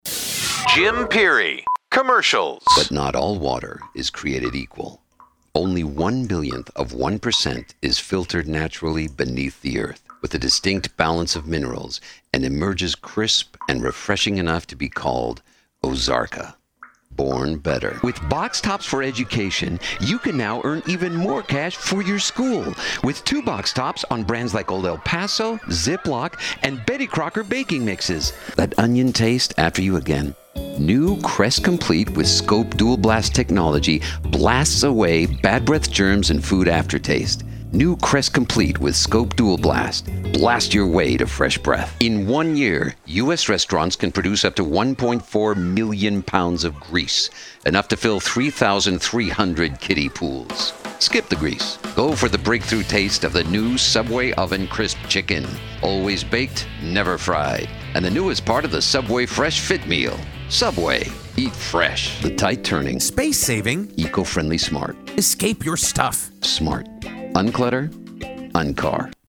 Female Commercial Demo #2